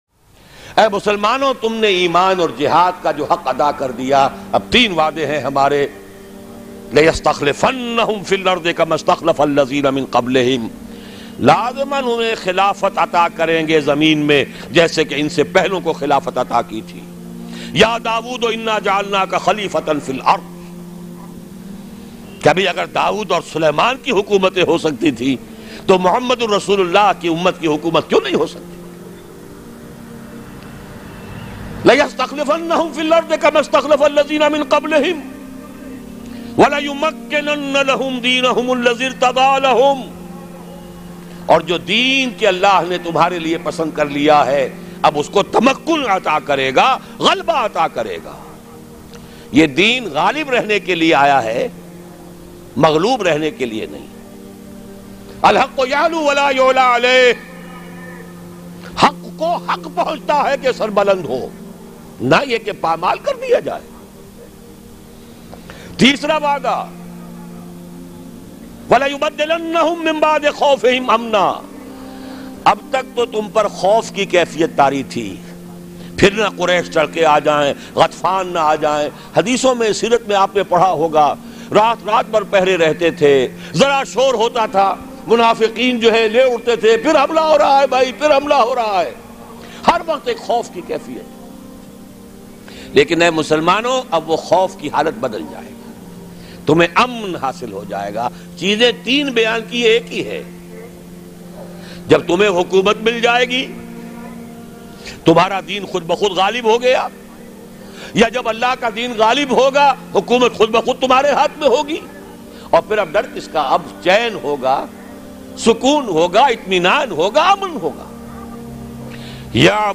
ALLAH Ke Umat Muslims Se 3 Waday Bayan MP3 Download By Dr Israr Ahmad